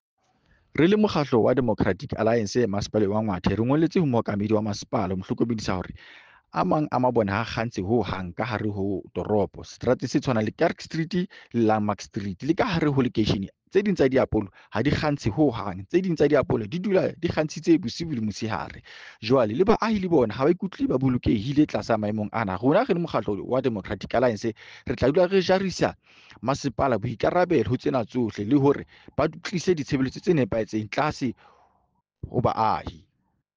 Sesotho soundbite by Cllr Joseph Mbele.